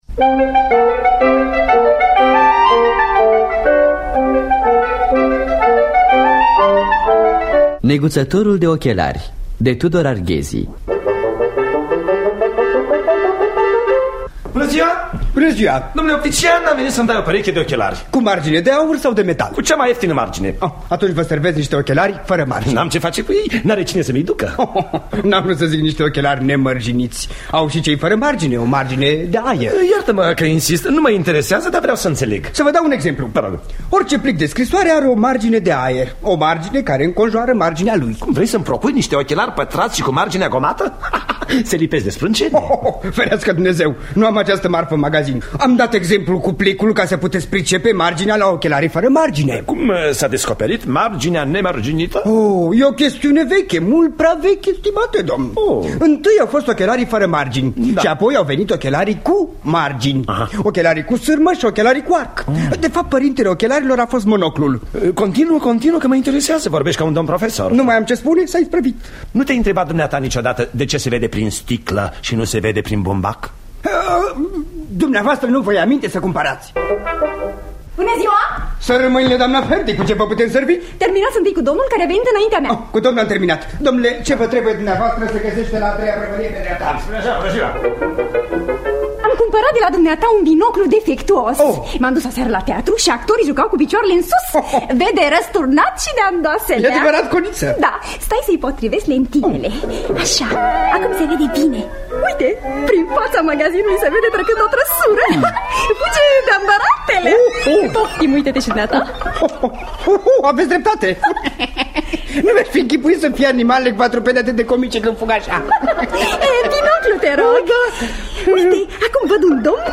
Adaptarea radiofonică de Mitzura Arghezi.
În distribuție: Gheorghe Dinică, Marin Moraru, Mitzura Arghezi.